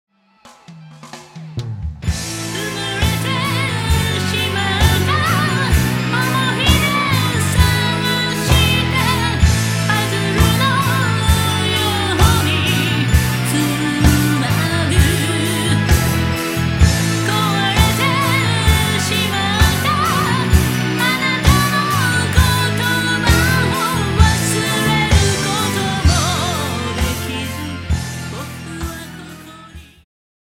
ジャンル Progressive
シンフォニック系
ハード系
2010年スタジオ録音